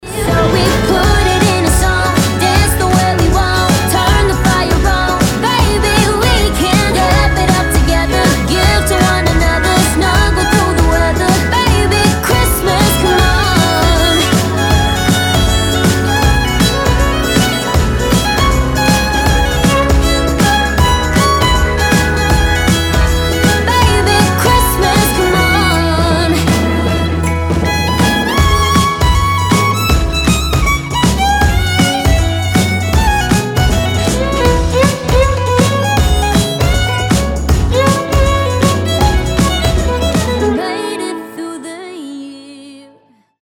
• Качество: 320, Stereo
женский вокал
скрипка
праздничные